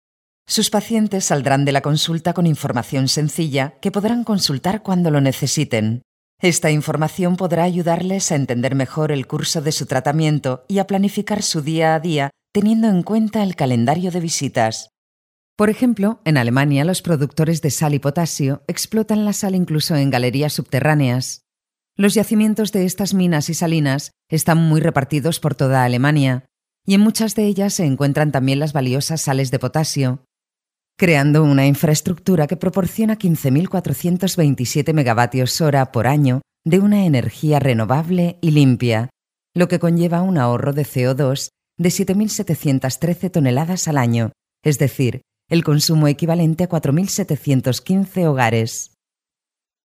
kastilisch
Sprechprobe: Industrie (Muttersprache):